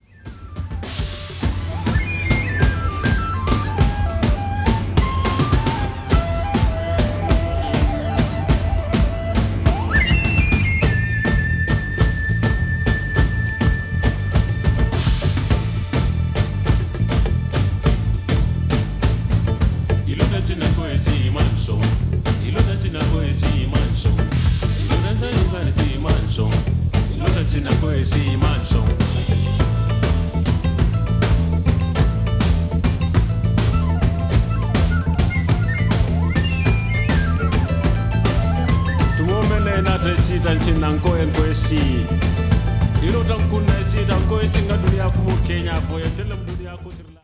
Recorded at Amuse Studio and Metal Box Studio, Tokyo, Japan
Mixed at the Hit Factory, New York City
drums, drum machine, percussion
kora, talking drum, vocals
trumpet, synthesizer
alto saxophone, clarinet, soprano saxophone, shanai
Japanese flute